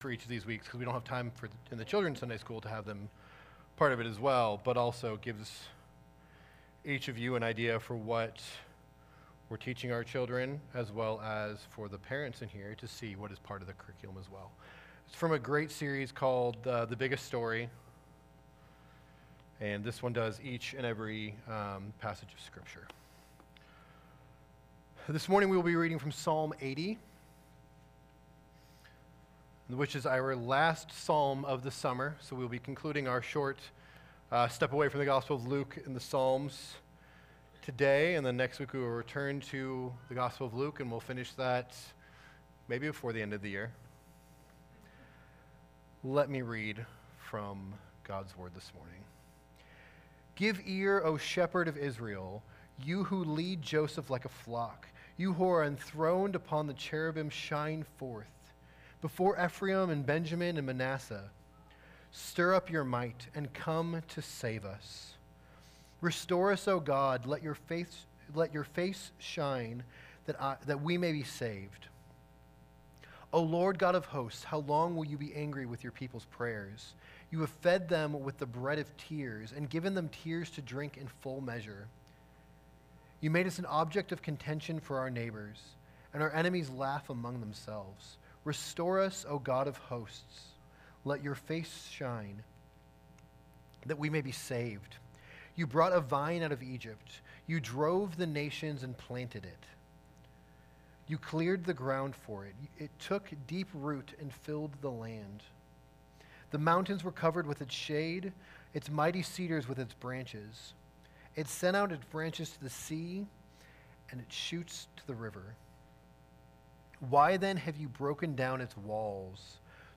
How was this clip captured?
Summer in the Psalms 2023 Sunday Morning Service